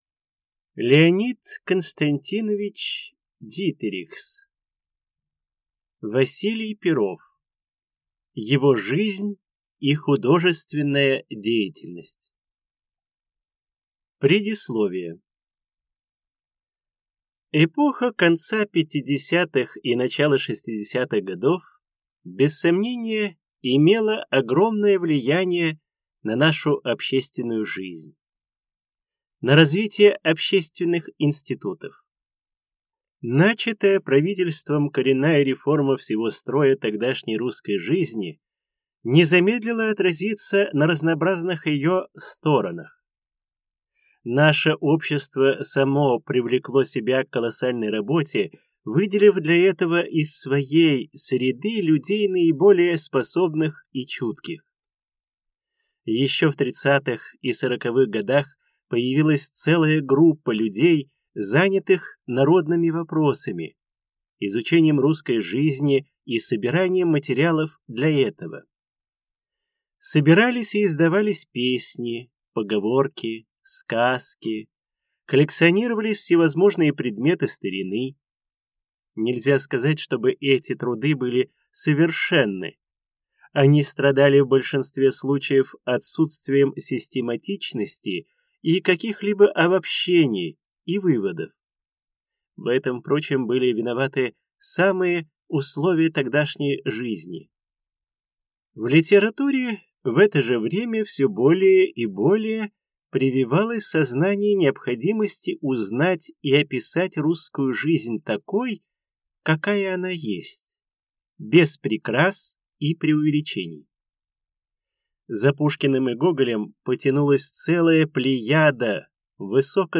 Аудиокнига Василий Перов. Его жизнь и художественная деятельность | Библиотека аудиокниг